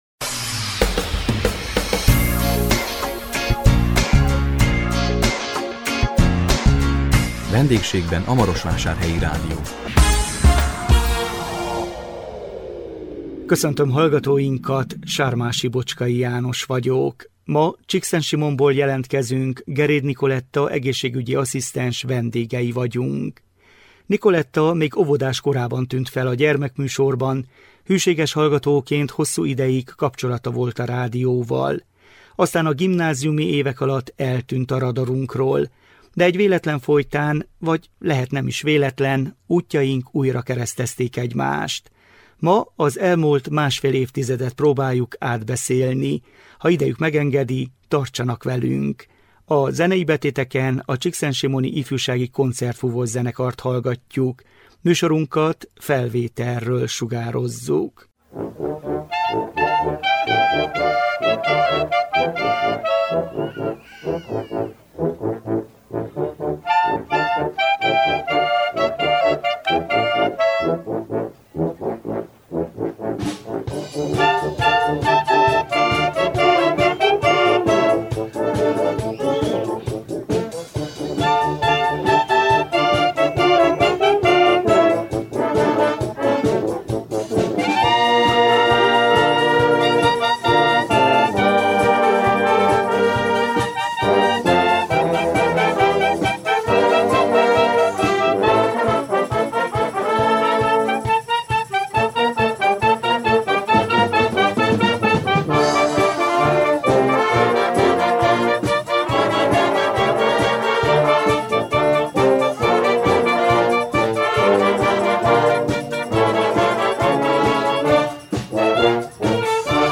A 2026 február 19-én közvetített VENDÉGSÉGBEN A MAROSVÁSÁRHELYI RÁDIÓ című műsorunkkal Csíkszentsimonból jelentkeztünk